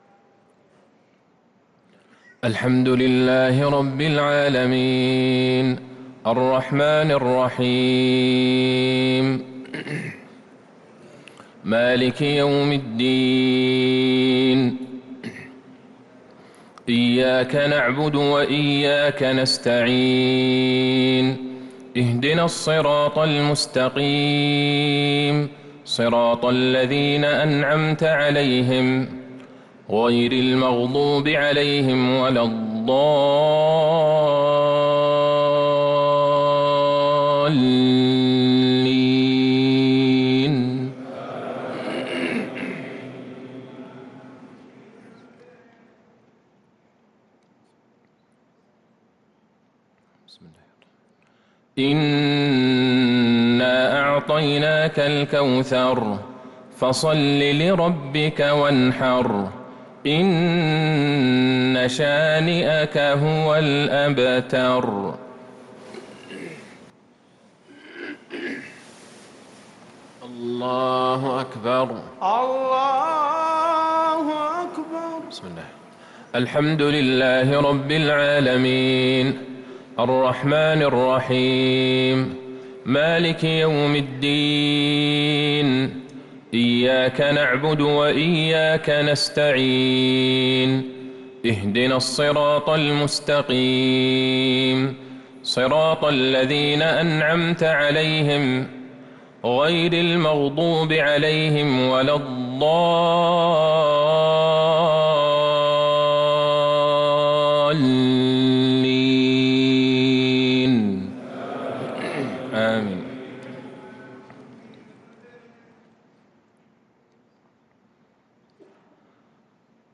صلاة المغرب للقارئ عبدالله البعيجان 17 رمضان 1443 هـ